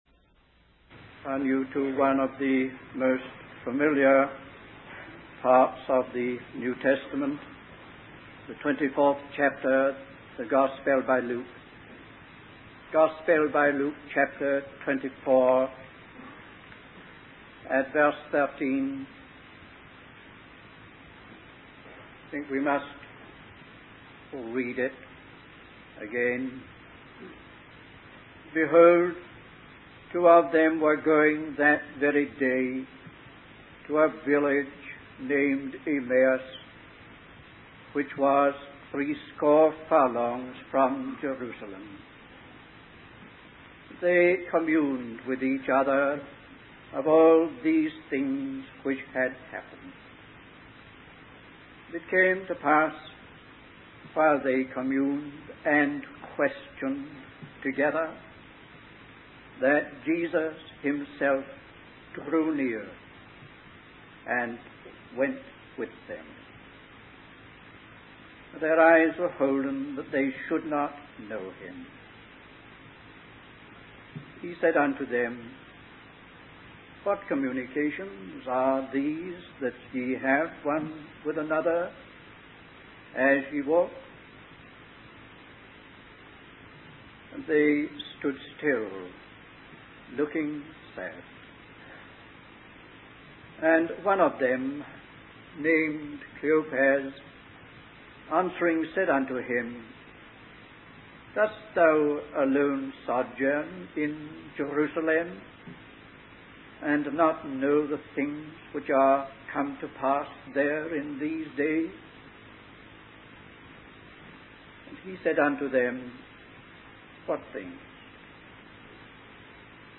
In this sermon, the preacher focuses on the story of two disciples who were walking to a village called Emmaus after the crucifixion of Jesus.